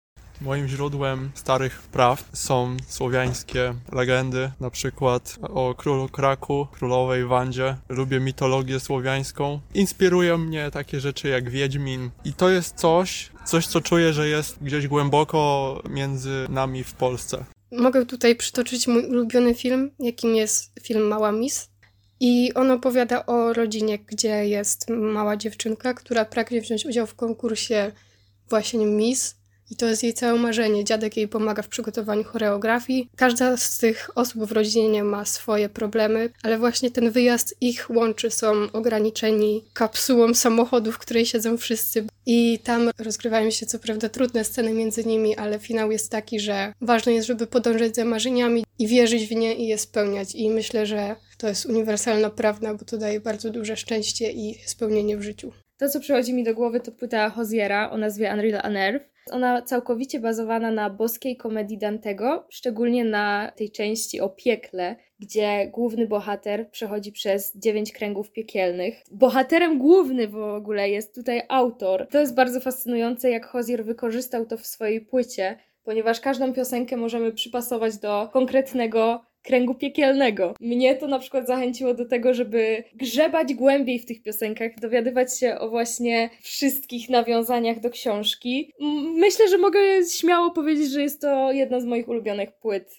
Zapytaliśmy studentów, czy mają jakieś swoje własne prastare opowieści (literatura, muzyka, film) powstałe już jakiś czas temu, a są dla nich źródłem uniwersalnych prawd.